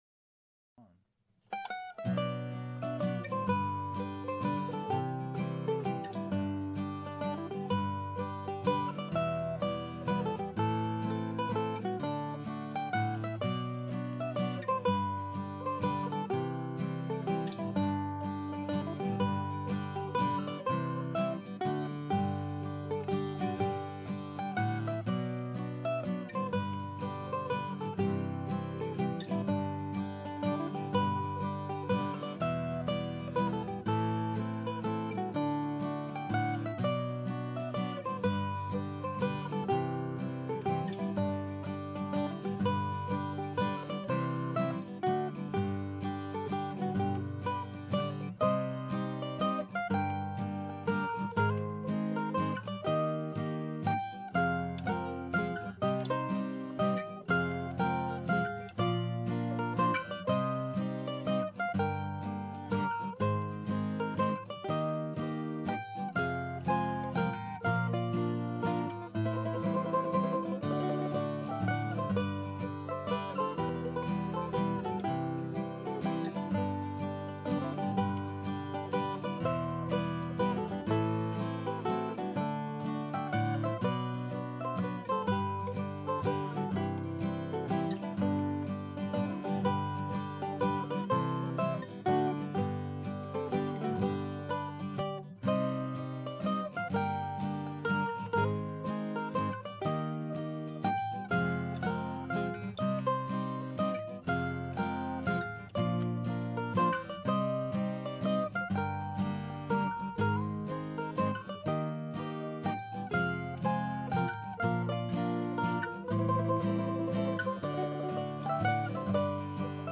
The 8th notes are meant to be bouncy and not straight as you can hear on the recording.